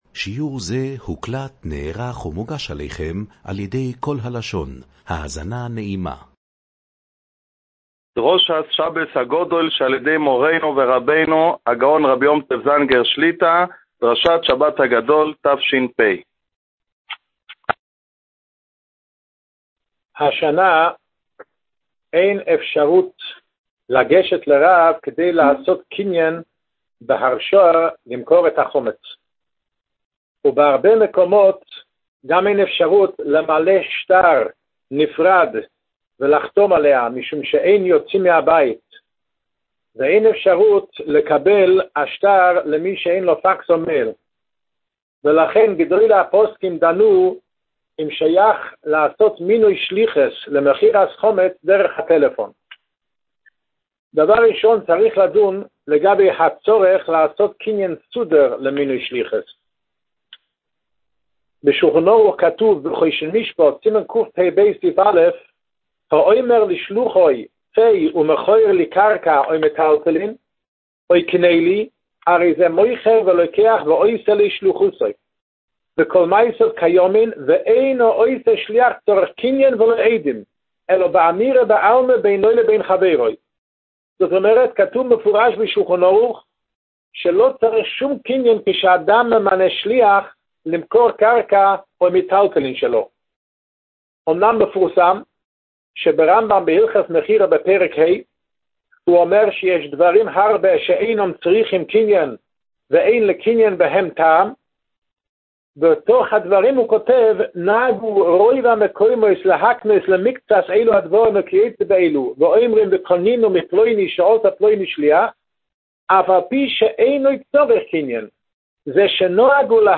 דרשת חיזוק ערב פסח תש"פ